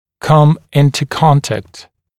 [kʌm ‘ɪntə ‘kɔntækt][кам ‘интэ ‘контэкт]войти в контакт, войти в соприкосновение